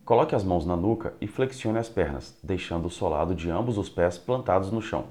I have two voice recordings… one that sounds good, and another that’s a bit muffled.